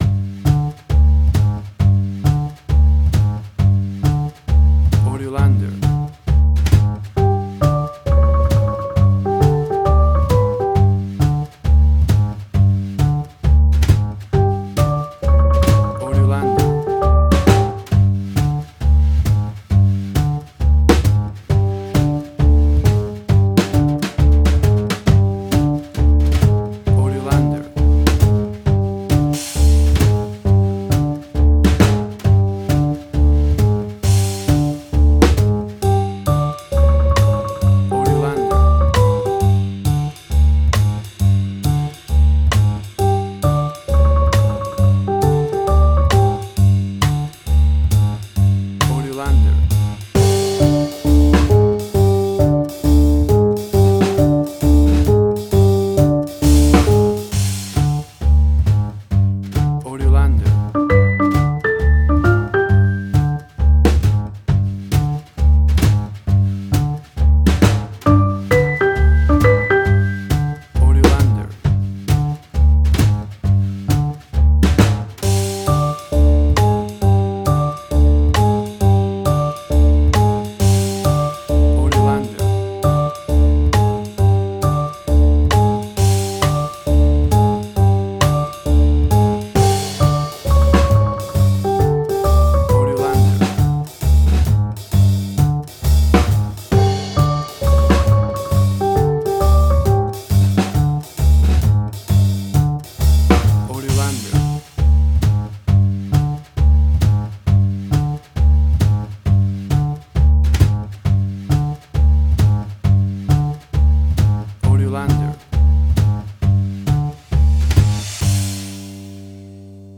Suspense, Drama, Quirky, Emotional.
Tempo (BPM): 134